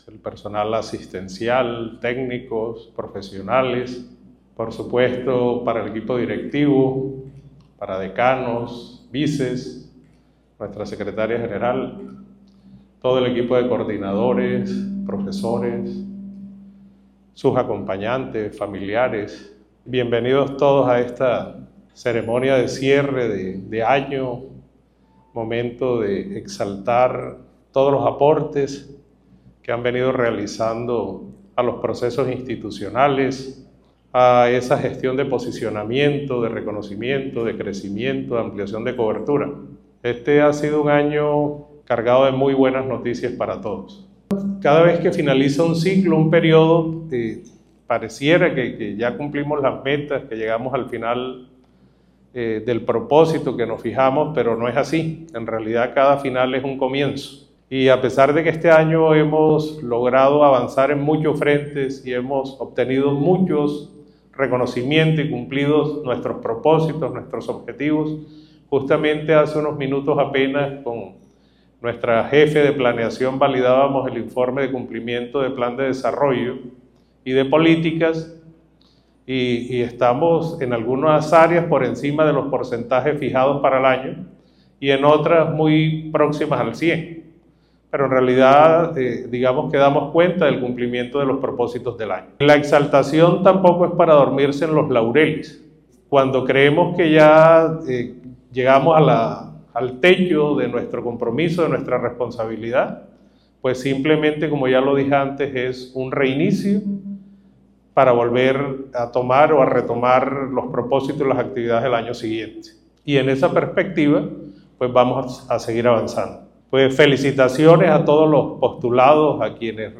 La Institución Universitaria del Caribe realizó en el Teatro Municipal la gala “La Noche de Los Mejores”, donde reconoció a profesores y funcionarios